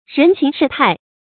人情世態 注音： ㄖㄣˊ ㄑㄧㄥˊ ㄕㄧˋ ㄊㄞˋ 讀音讀法： 意思解釋： 人世間的情態。多指人與人之間的交往情分。